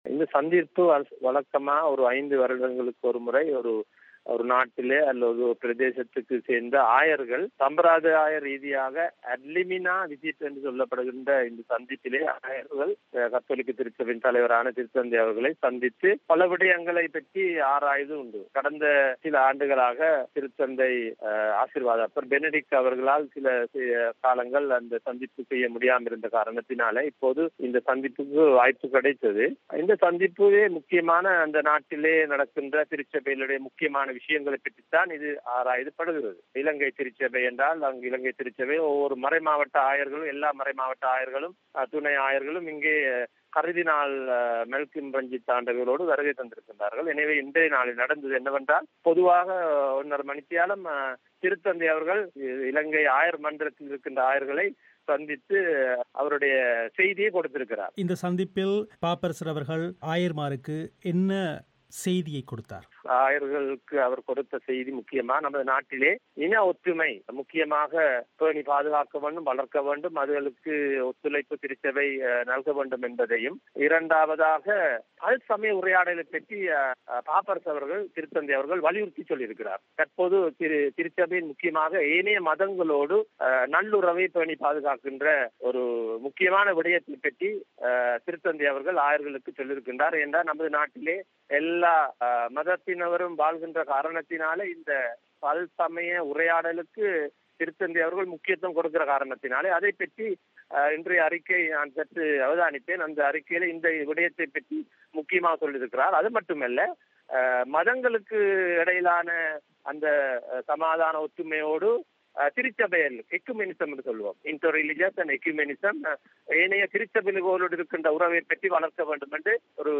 பிபிசி தமிழோசைக்கு அளித்த செவ்வி